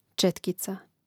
čȅtkica četkica im. ž. (G čȅtkicē, DL čȅtkici, A čȅtkicu, I čȅtkicōm; mn.